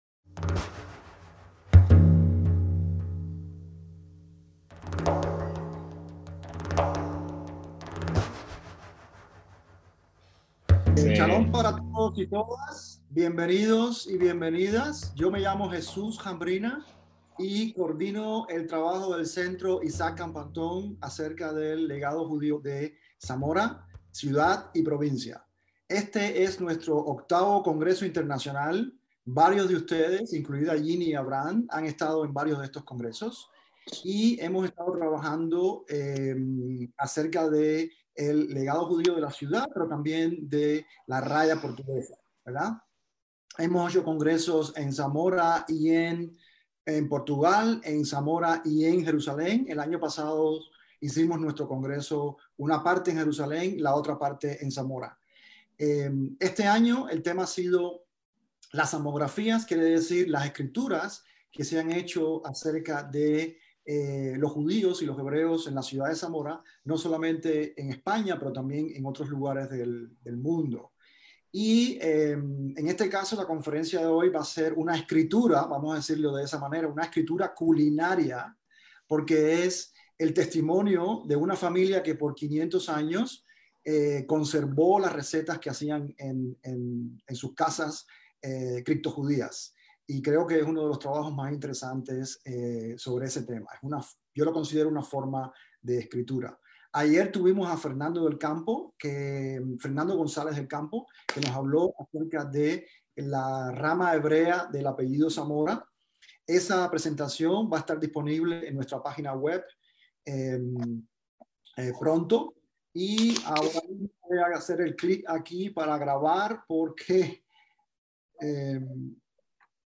ACTOS "EN DIRECTO" - Una familia judía originaria de Fermoselle, España, conservó apuntes de las recetas que durante siglos cocinaron por aquellos lugares donde vivieron. En cada sitio agregaron ingredientes locales para respetar las leyes casher y también enmascararon platos para evadir la inquisición y los prejuicios contra los judíos. Cinco siglos después, estas recetas se convierten en evidencias de cómo los criptojudíos de La Raya entre España y Portugal comían y celebraban las festividades de sus ancestros en el resguardo de sus casas.